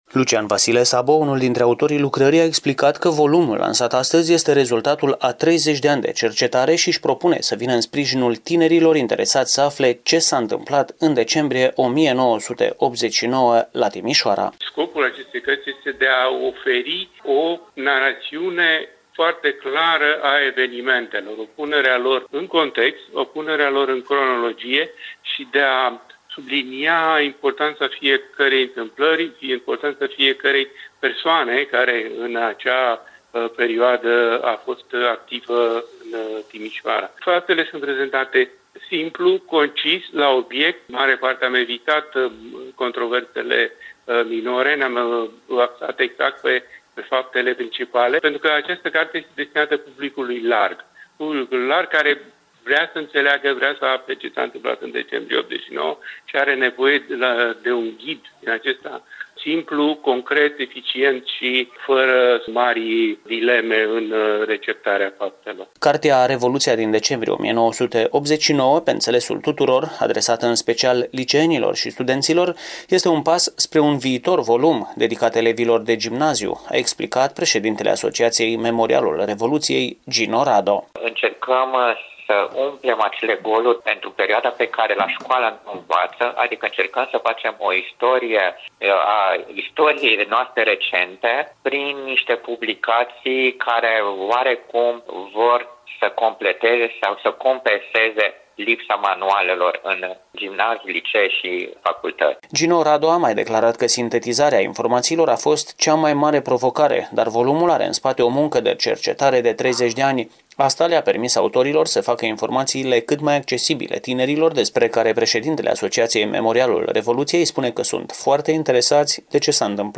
O carte despre Revoluția din Decembrie 1989, dedicată elevilor de liceu și studenților, a fost lansată astăzi la Memorialul Revoluției din Timișoara.